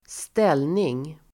Uttal: [²st'el:ning]